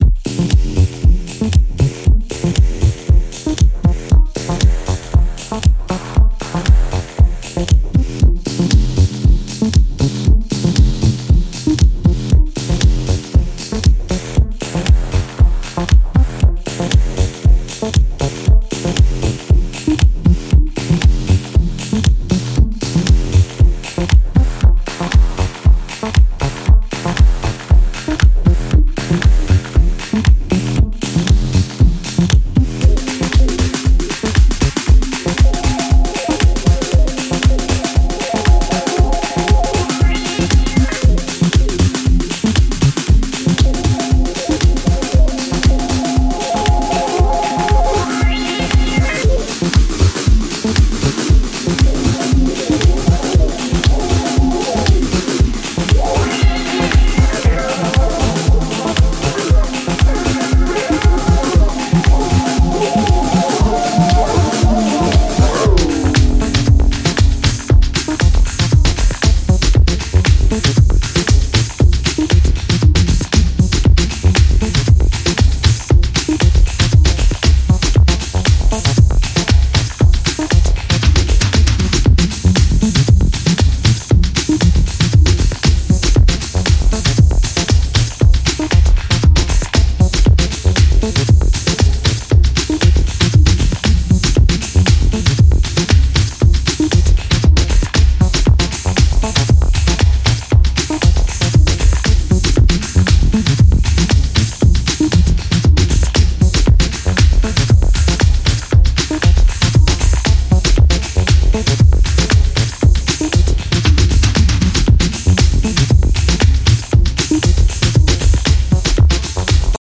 うねる深いダブの波間にアシッドリフが見え隠れするディープ・ハウス